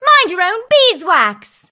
bea_hurt_vo_02.ogg